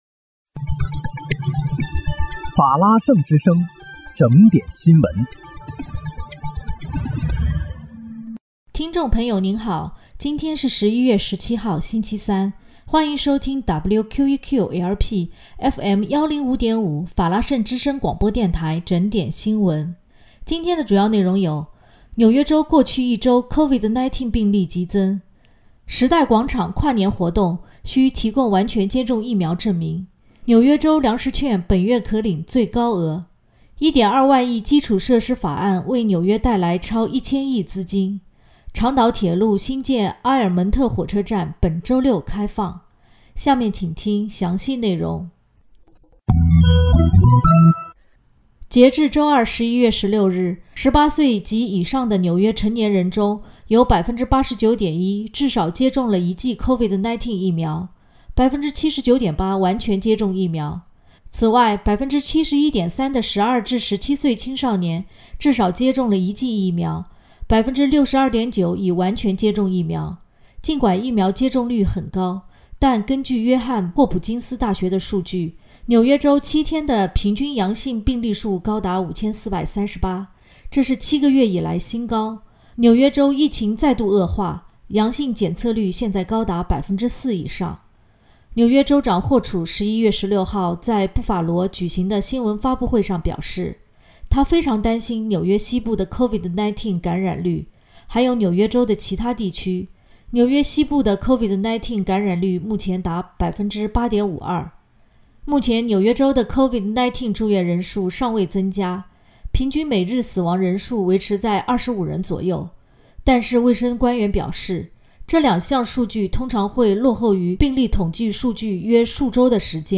11月17日（星期三）纽约整点新闻